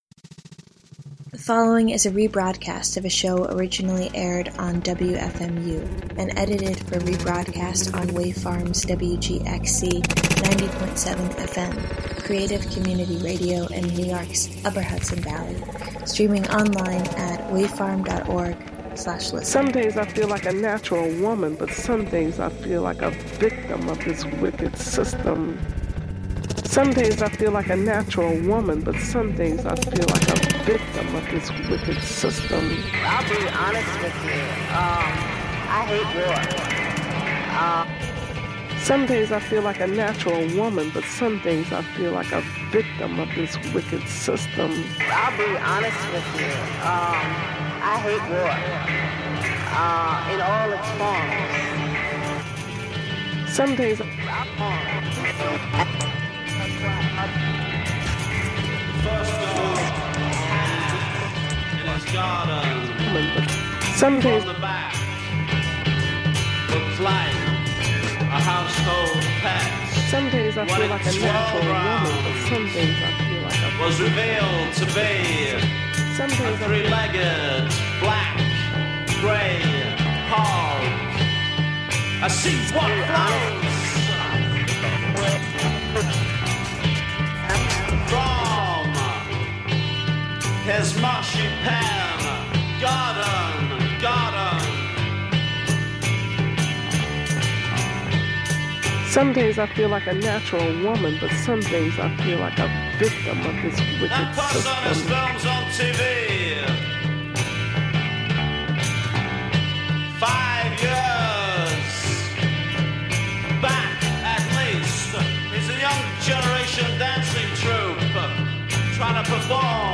In a stew of intimate electronics, bad poetry, and tender murk, we swap tongues and reach for more.